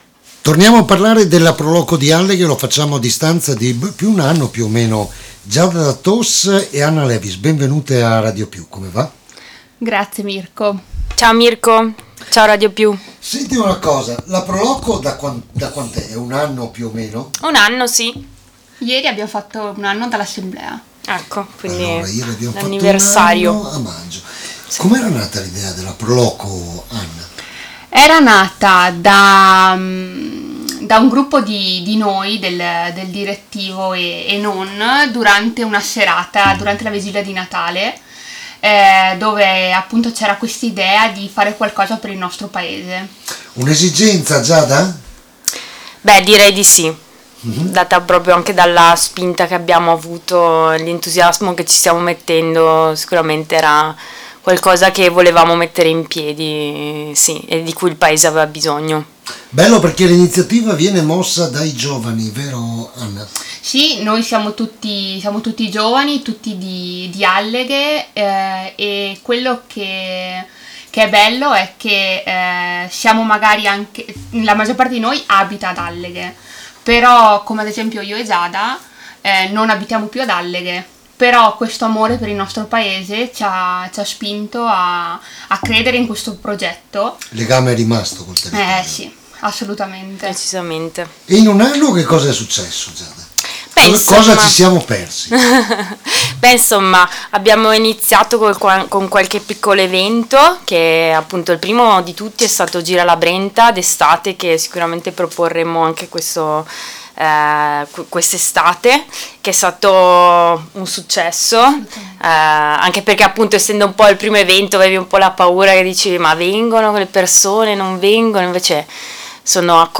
L’INTERVISTA ALLA RADIO